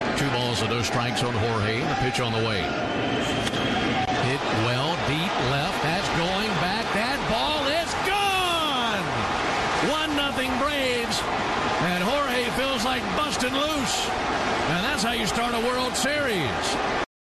PBP-Braves-1-0-Soler-Solo-HR.mp3